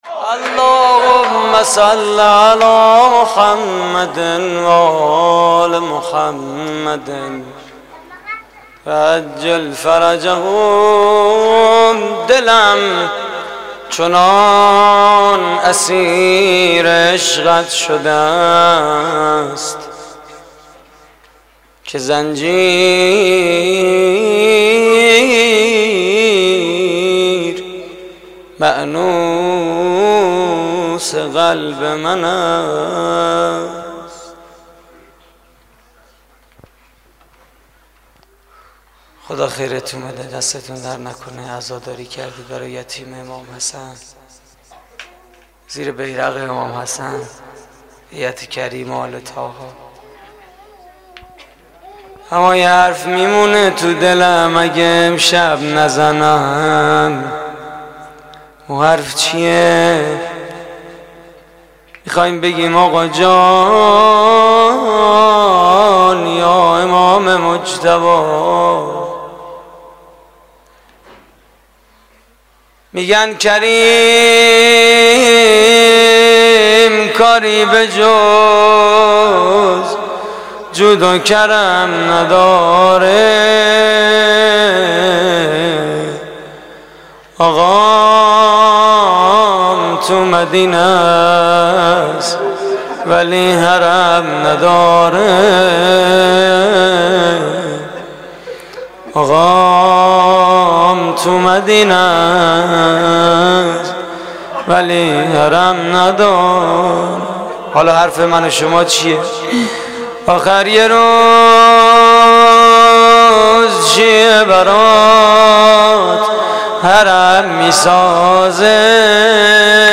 مراسم عزاداری شب پنجم محرم 1432 / هیئت کریم آل طاها (ع) – شهرری؛ 9 آذر 1390
روضه پایانی: کریم کاری به جز جود و کرم نداره؛ پخش آنلاین |